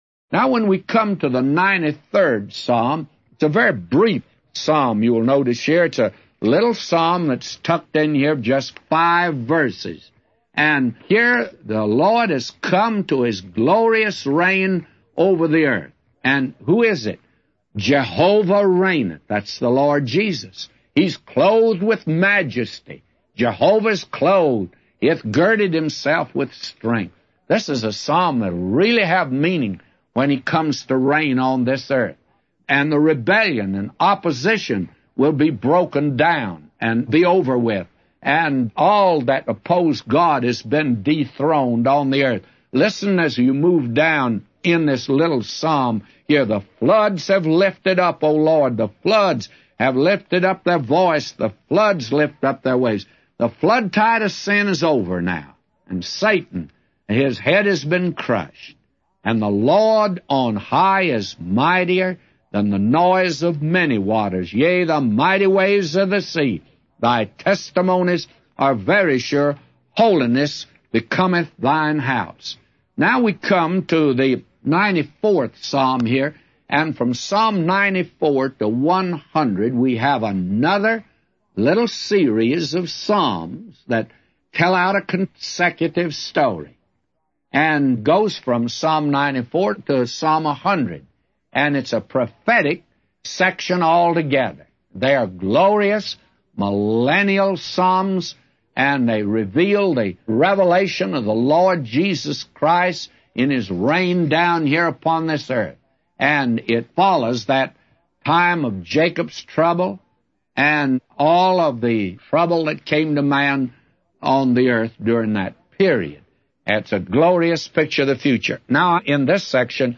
A Commentary By J Vernon MCgee For Psalms 93:1-999